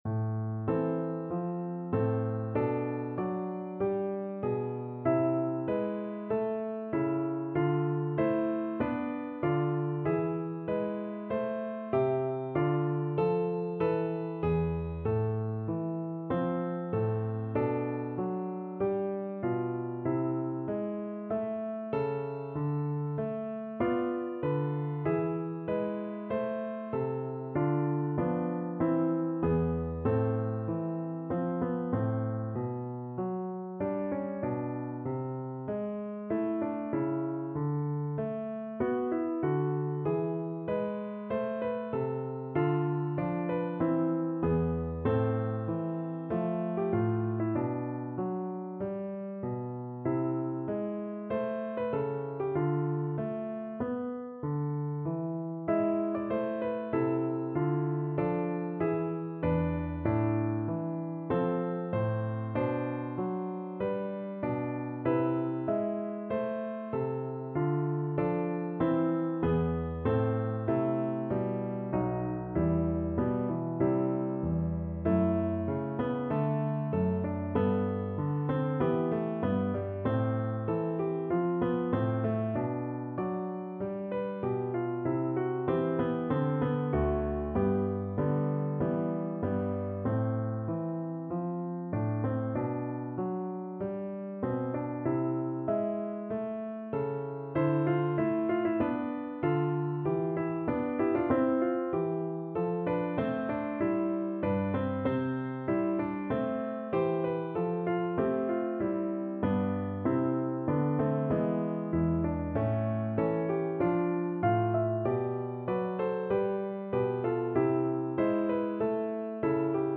A minor (Sounding Pitch) (View more A minor Music for Recorder )
4/4 (View more 4/4 Music)
Classical (View more Classical Recorder Music)